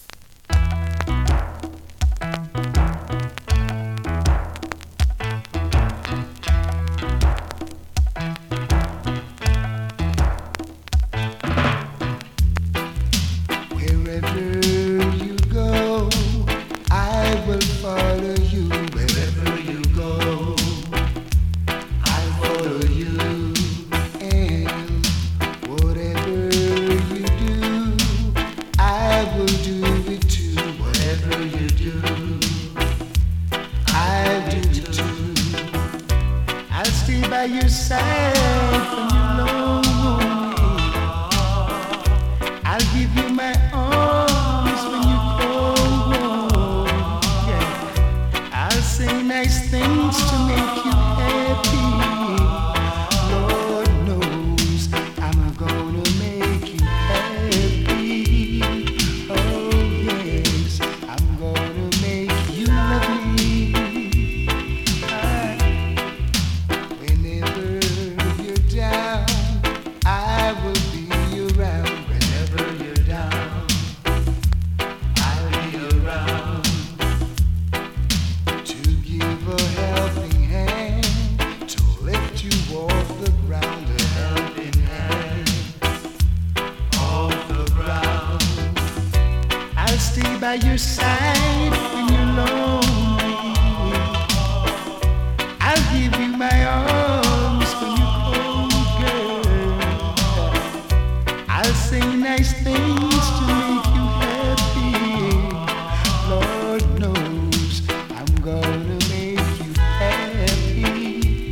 2025 NEW IN!! DANCEHALL!!
スリキズ、ノイズ比較的少なめで